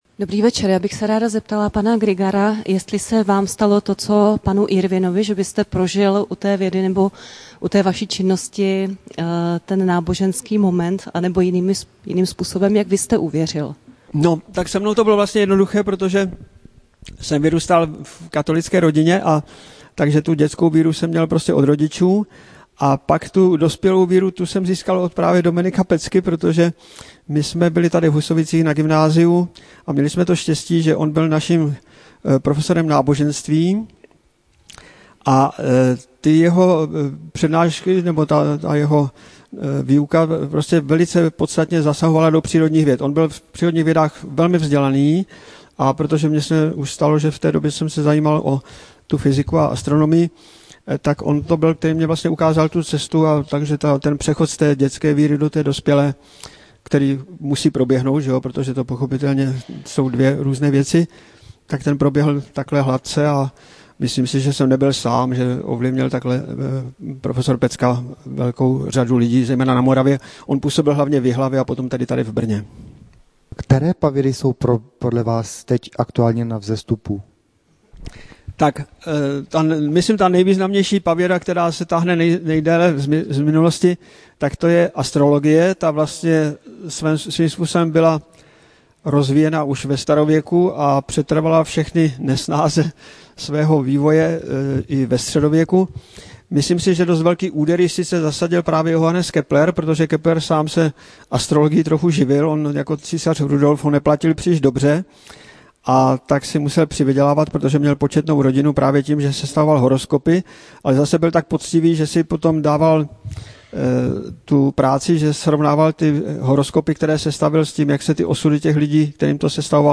Tělocvična se zaplnila do poslední židle a míst na stání v průběhu přednášky postupně také ubývalo. Tento energický muž, dalo by se říct jedním dechem, odvyprávěl posluchačům historický vývoj vztahu mezi vědou a vírou. S lehkostí spojoval postoje a myšlenky fyziků, filozofů a teologů, jak se prolínaly, vylučovaly a podporovaly v plynutí času až do dnešní doby.